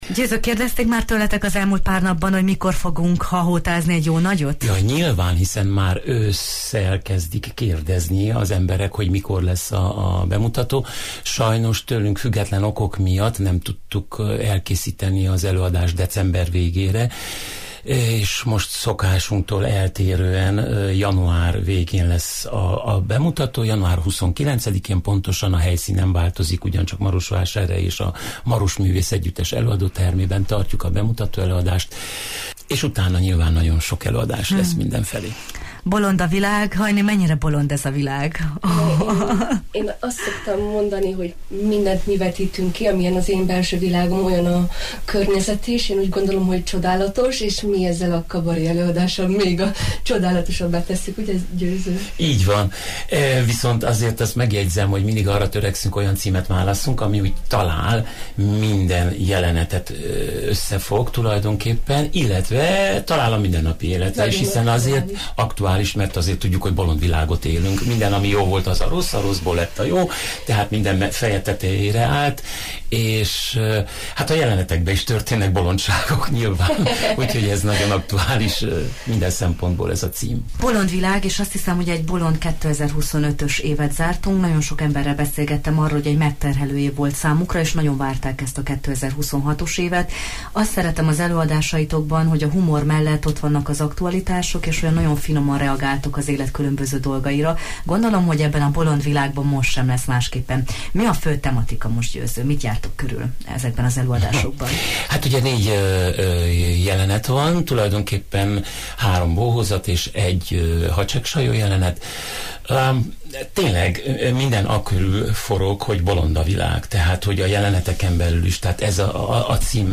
kérdeztük az idei előadás részleteiről a Jó reggelt, Erdély!-ben: